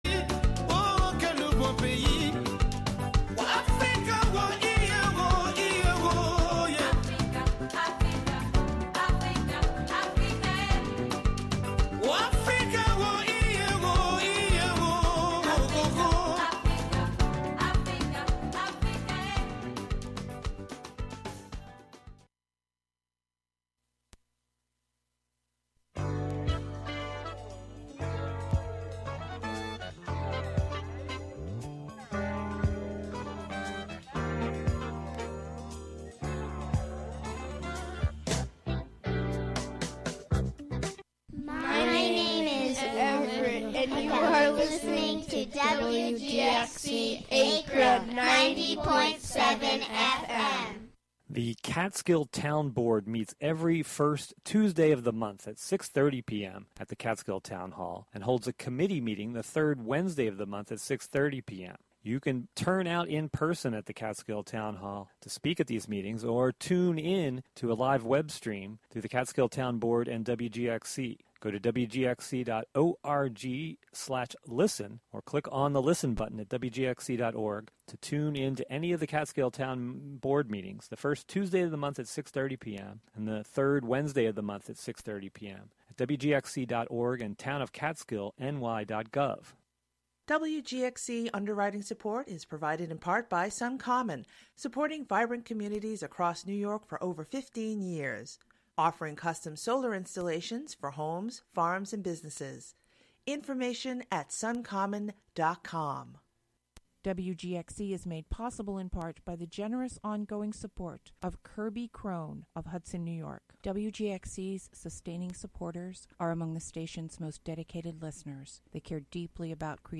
tenor sax
pioneering post-bop albums